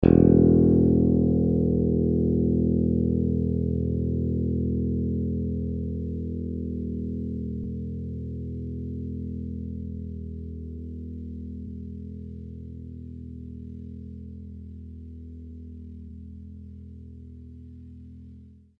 bass-electric
E1.mp3